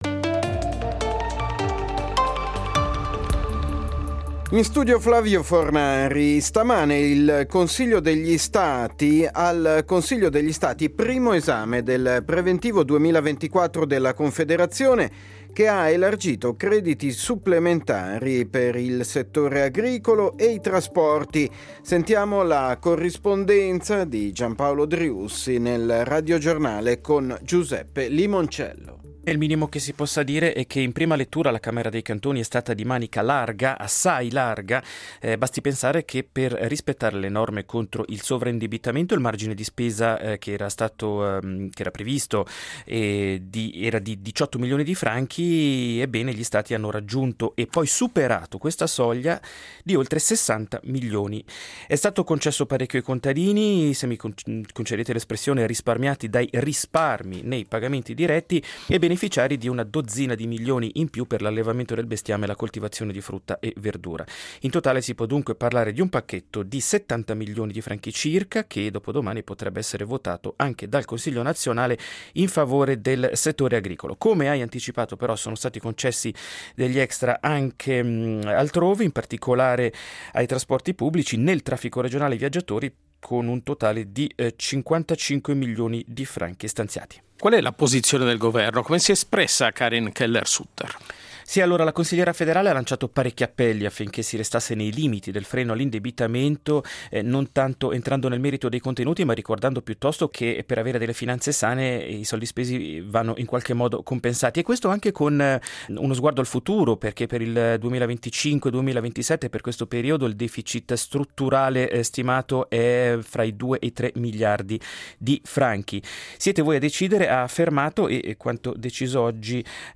Notiziario delle 14:00 del 05.12.2023